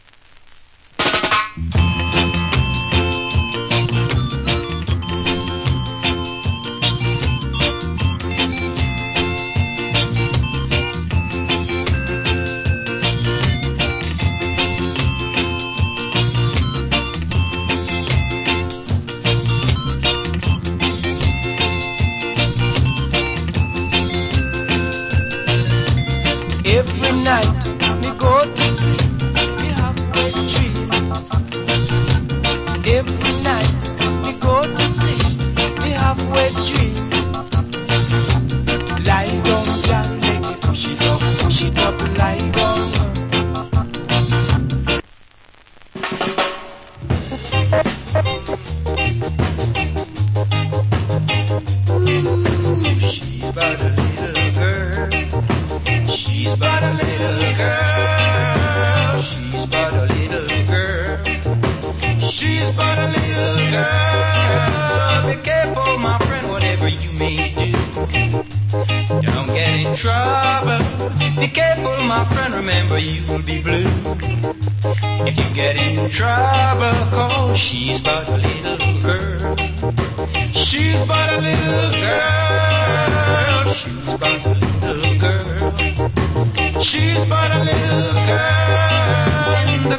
Big Shot Early Reggae.